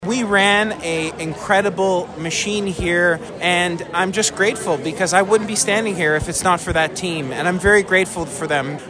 We caught up with Piccini following the news of his re-election.